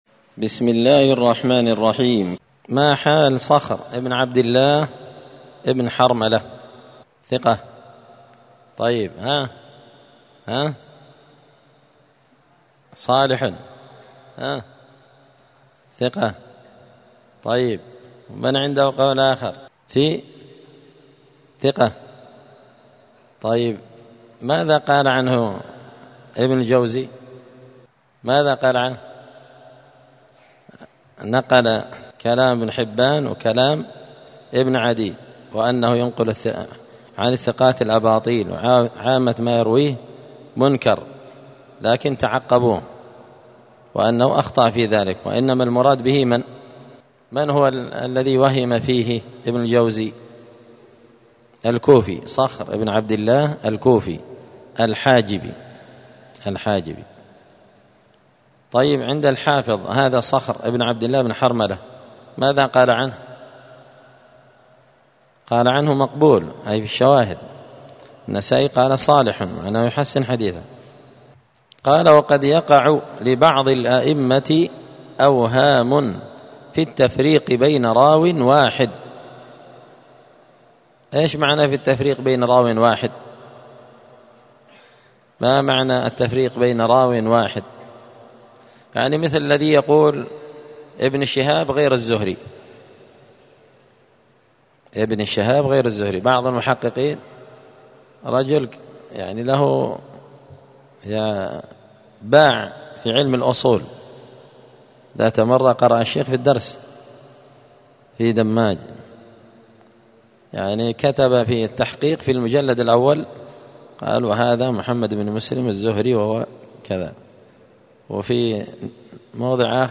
دار الحديث السلفية بمسجد الفرقان بقشن المهرة اليمن 📌الدروس اليومية